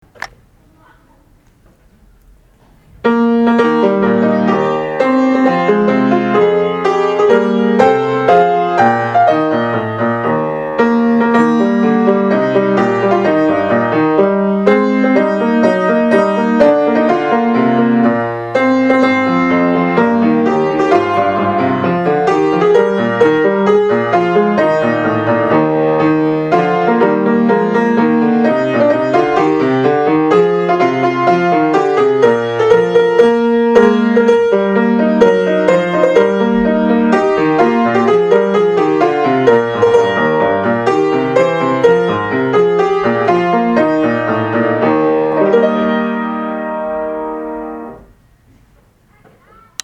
（伴奏が流れます　mp3形式　1173KB）